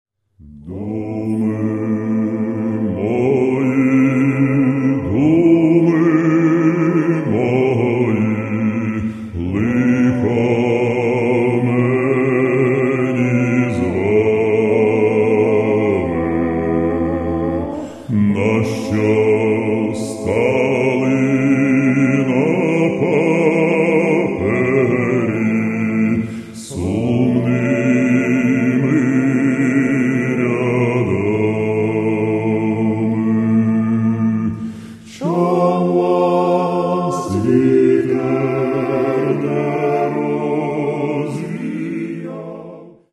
Народная